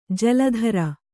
♪ jala dhara